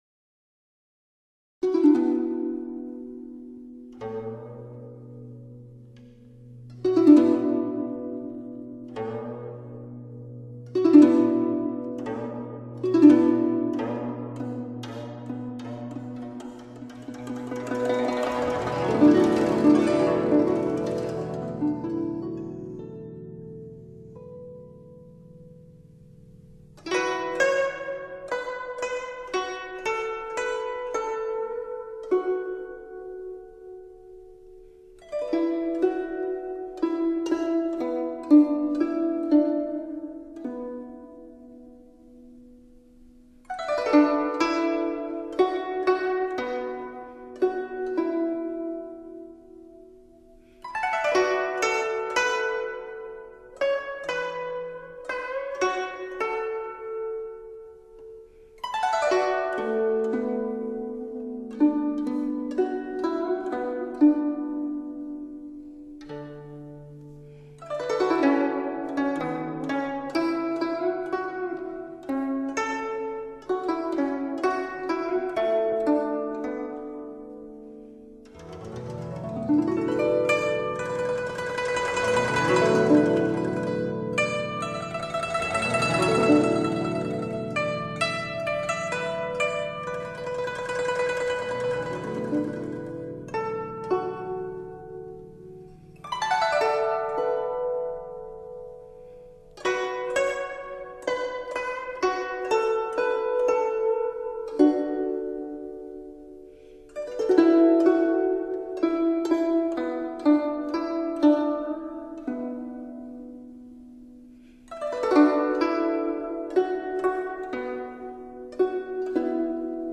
古筝音调清丽温婉，轻快通透。
和民族音乐，使东方传统乐器脱胎换骨，给你意想不到的惊喜......